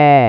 Stiimulitena kasutame eelmises peatükis kirjeldatud formantsünteesi abil genereeritud vokaalikontiinumit (vt ptk 17.3).
F3 väärtus ei muutu, see on kõigis stiimulites 2750 Hz. Seega võiks stiimulid esindada eesvokaale /i/-st /æ/-ni.
stim_F1_700.wav